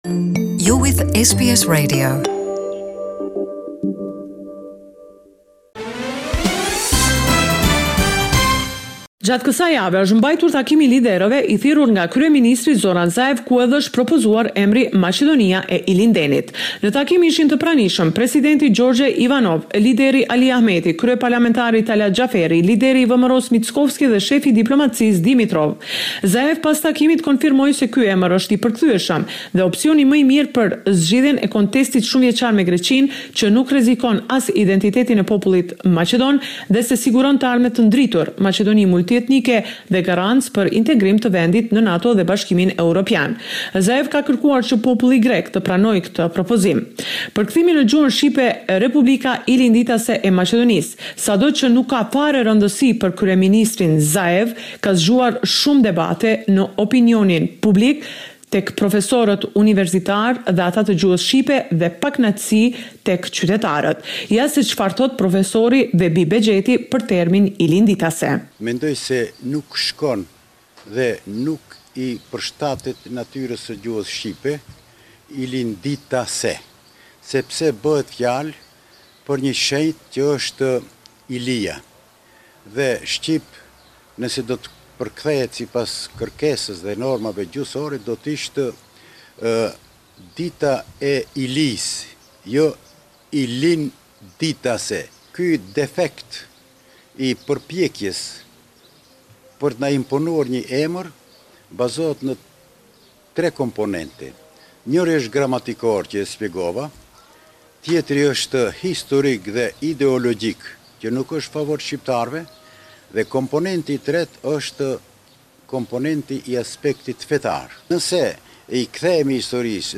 The weekly report with the latest developments in Macedonia.